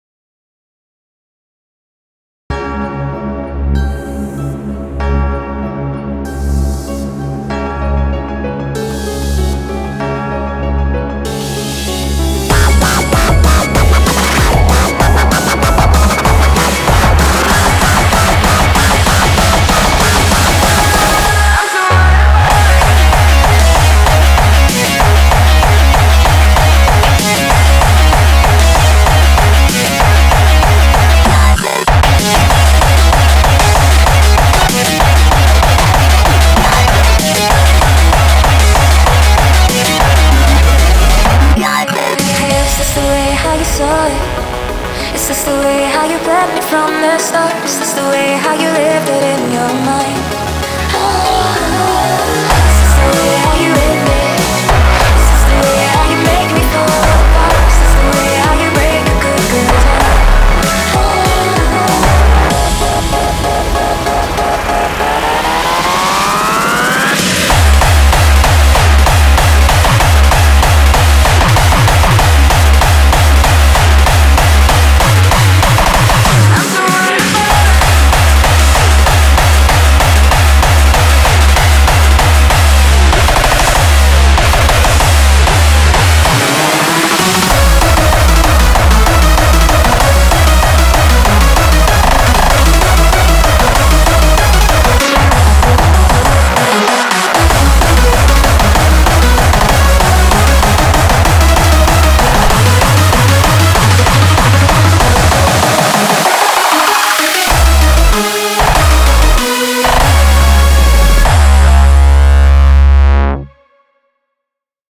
BPM96-192